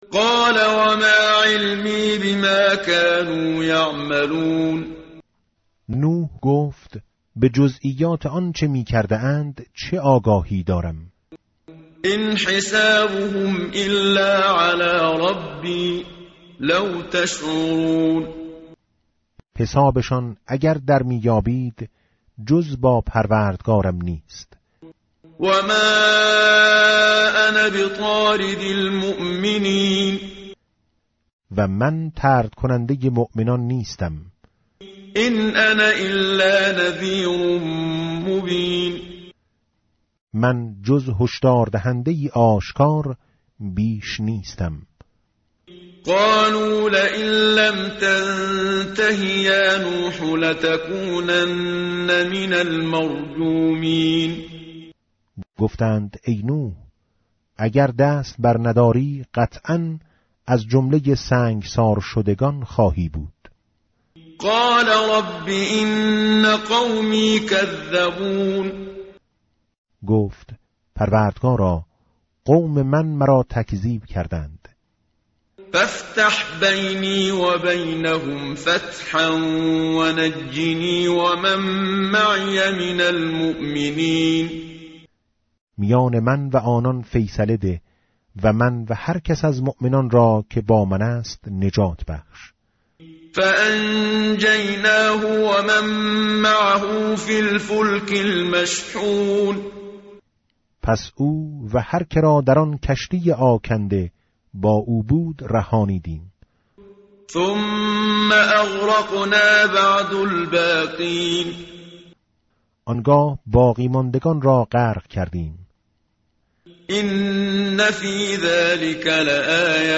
متن قرآن همراه باتلاوت قرآن و ترجمه
tartil_menshavi va tarjome_Page_372.mp3